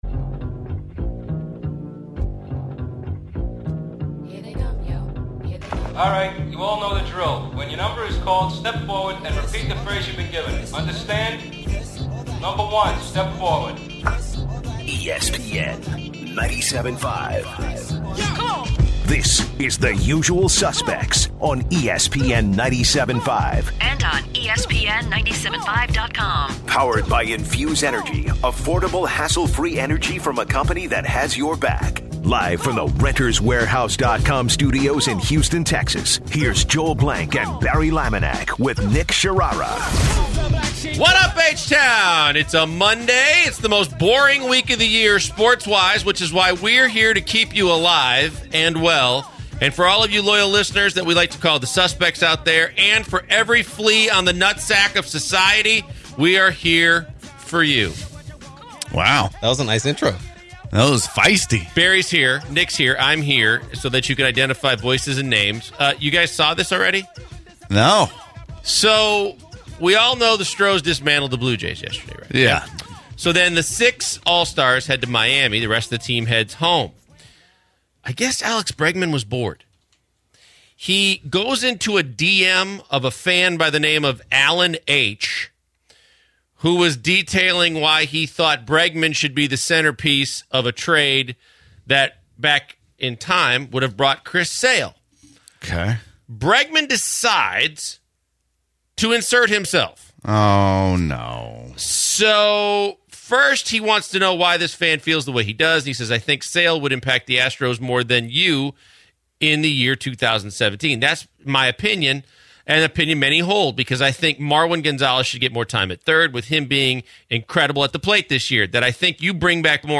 They finish up the hour talking about the aces the Astros should trade for, and end with an interview of Bruce Arians, Arizona Cardinals’ head coach.